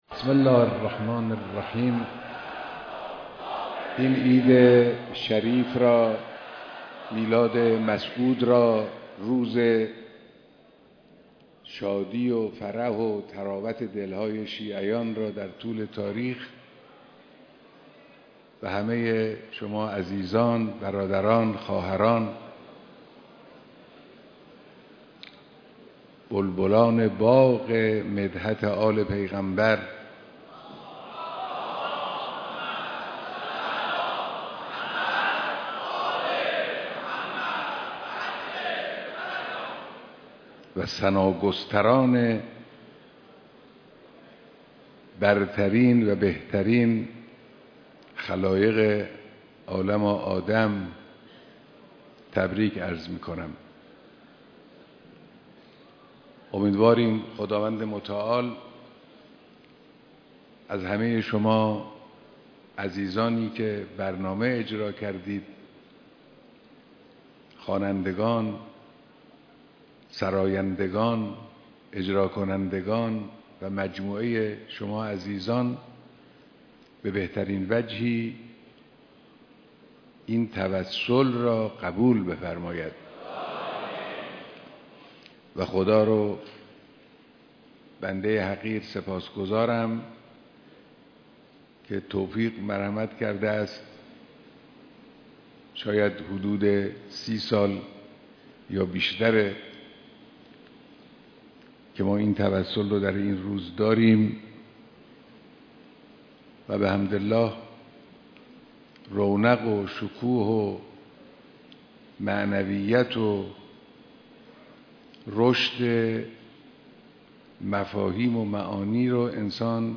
بیانات در دیدار جمعی از شاعران و ذاکران اهل بیت(ع)